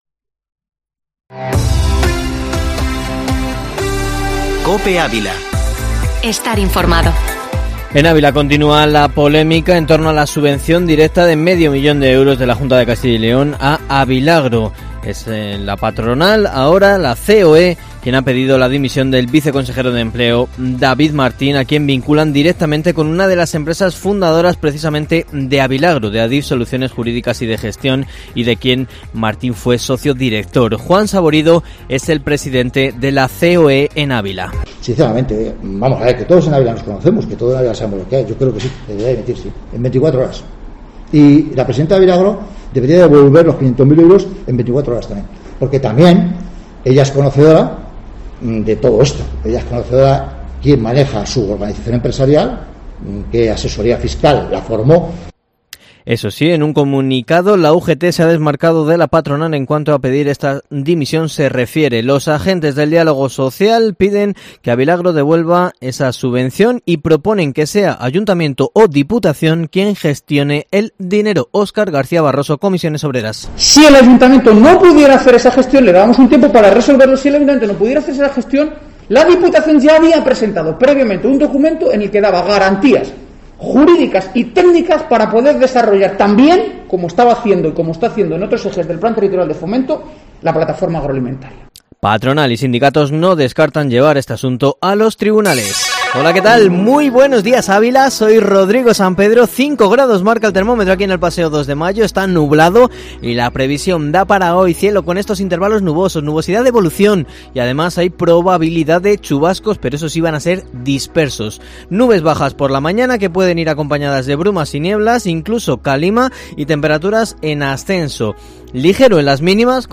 Informativo matinal Herrera en COPE Ávila 02/03/2021